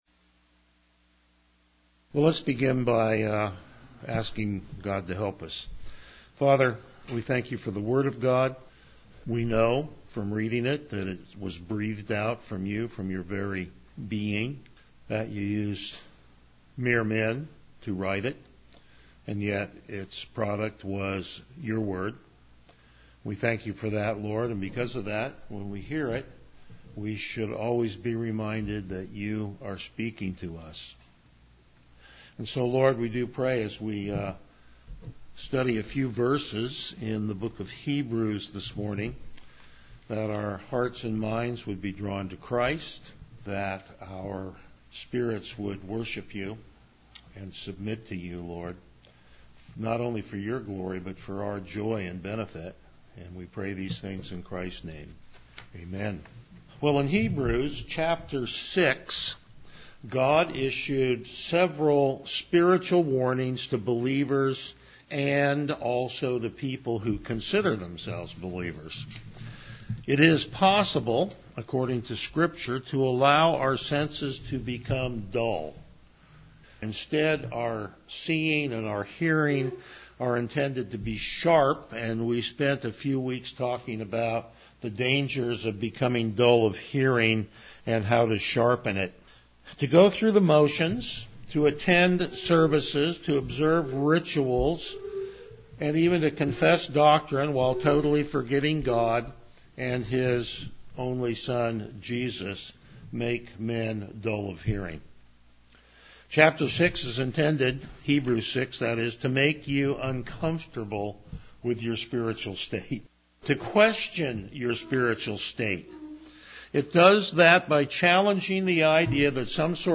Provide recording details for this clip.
Passage: Hebrews 7:11-14 Service Type: Morning Worship